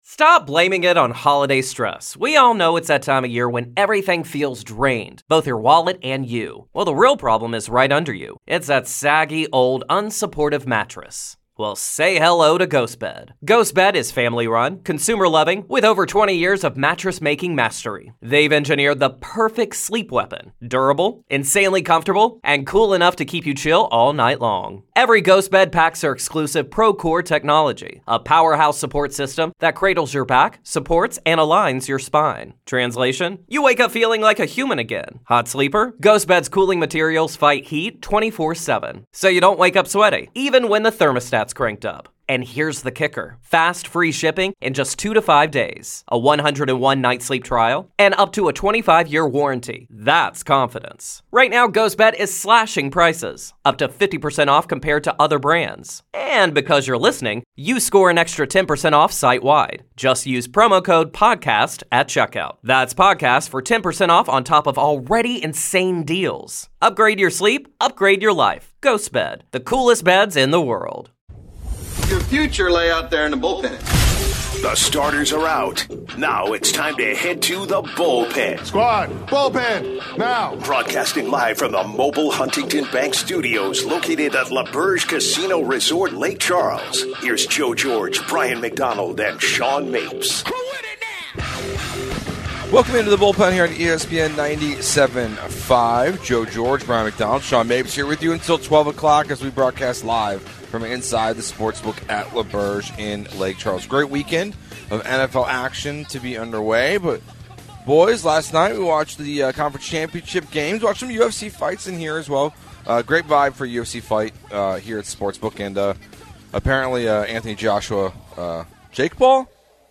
12/07/25 Hour 1 (Live from L'auberge in Lake Charles)-CFB Playoff Fallout + Game Picks + Do the Rockets Suck at Back to Backs?